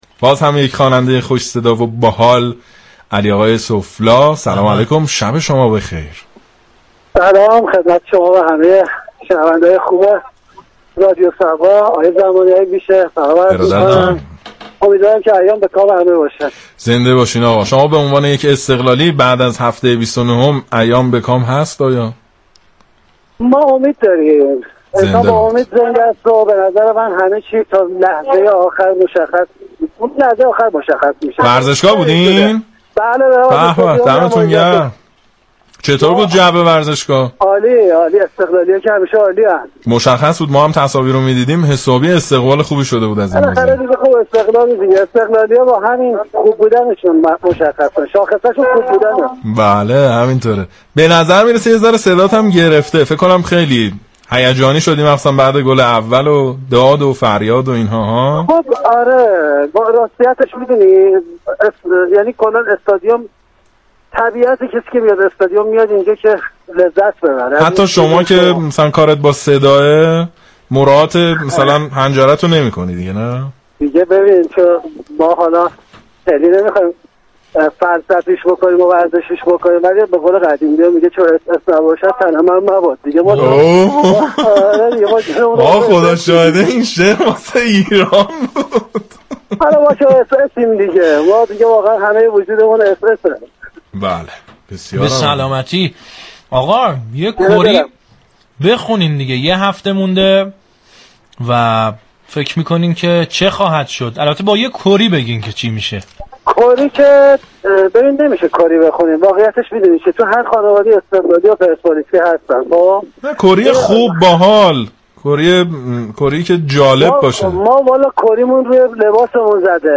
ویژه برنامه «زیر طاقی» همزمان با برگزاری بازی پایانی لیگ برتر به صورت زنده از این شبكه رادیویی تقدیم مخاطبان می شود.